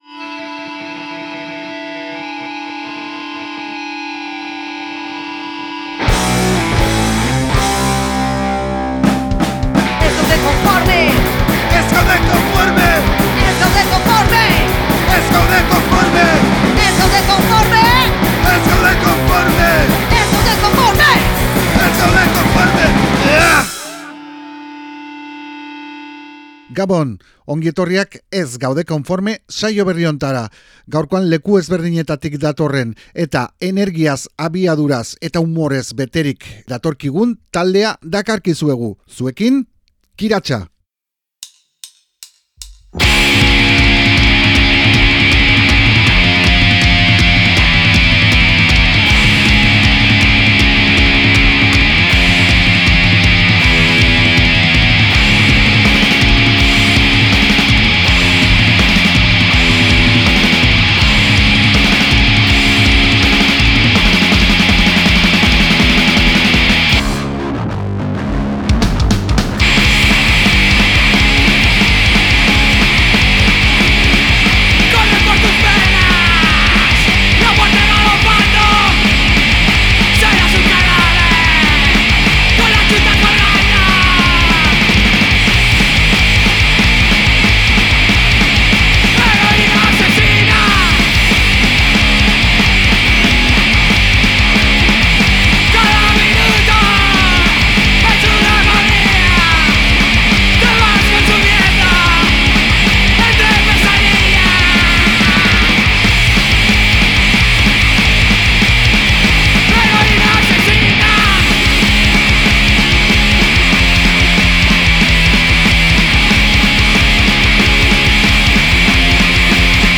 punk basati eta D-Beat indartsua
elkarrizketa dibertigarri bat